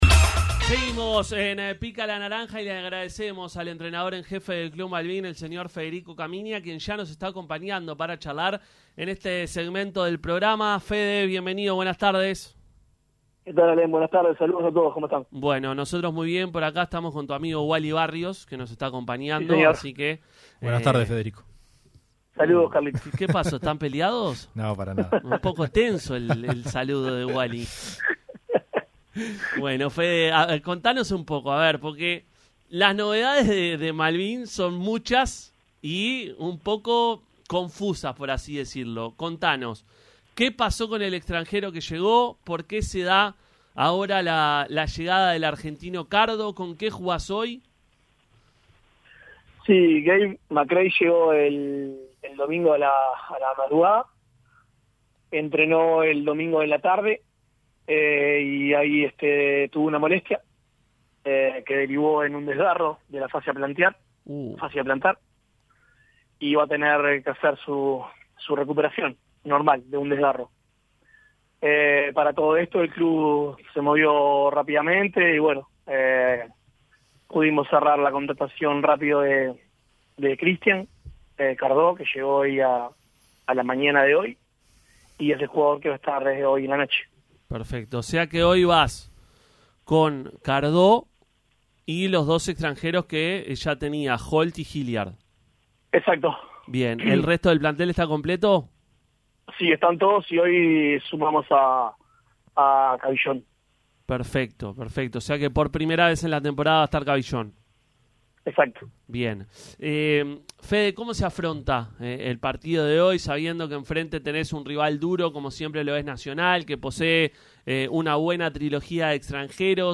pasó por los micrófonos de Pica la Naranja en la previa al juego de esta noche ante Nacional.